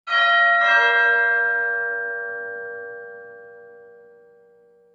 doorbell.wav